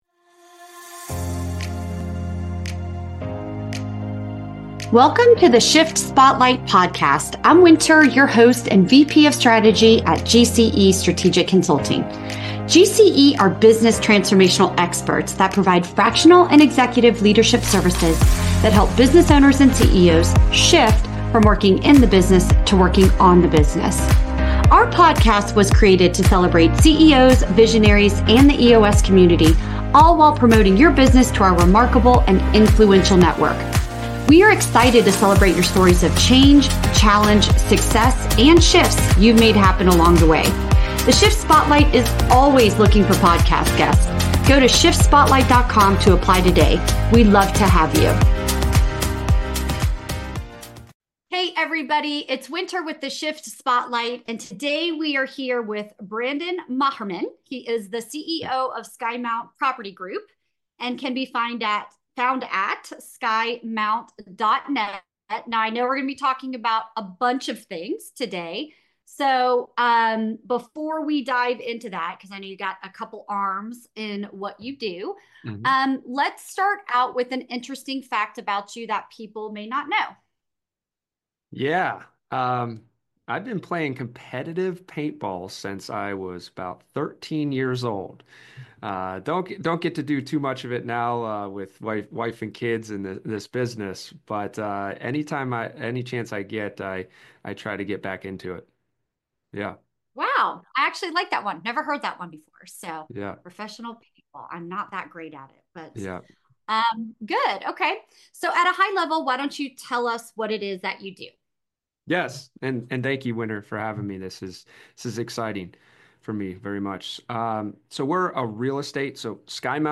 🔥 Inside this powerful conversation: